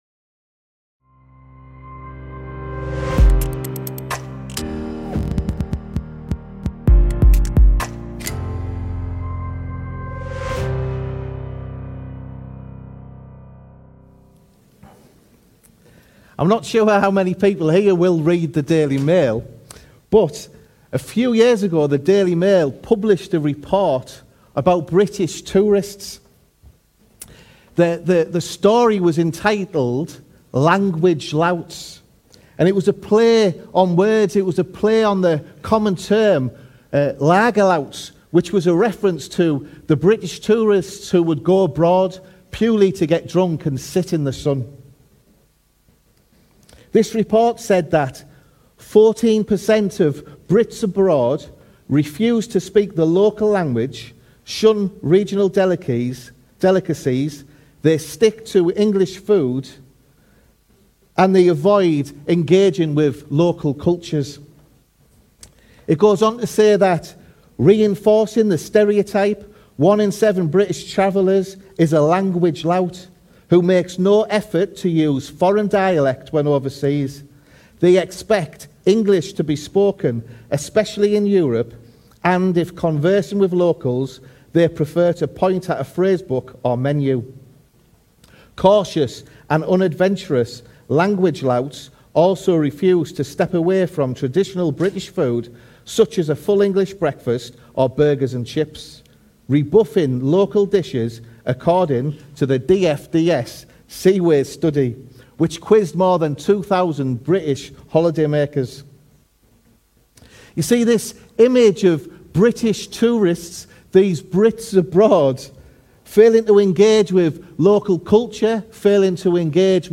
This weeks podcast is a recording of a sermon on Acts 15:1-35